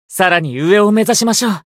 觉醒语音 向着更高的目标前进吧 さらに上を目指しましょう 媒体文件:missionchara_voice_373.mp3